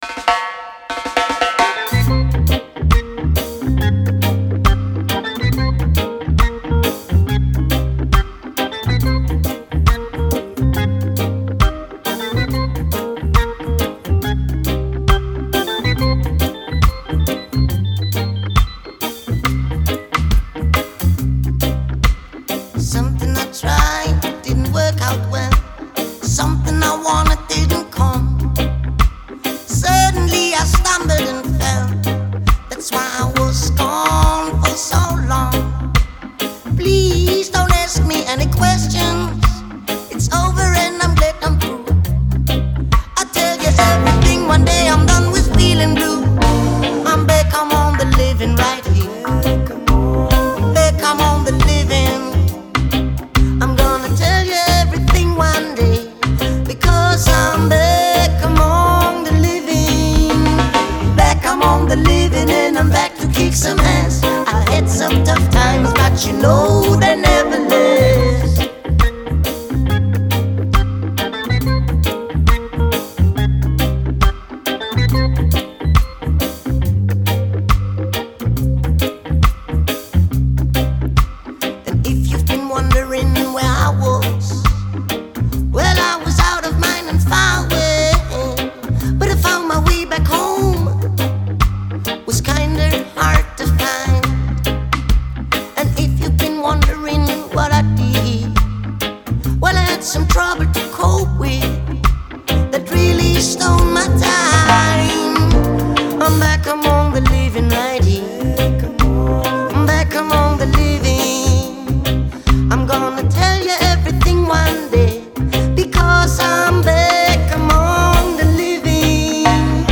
Vocais
Teclados
Baixo
Bateria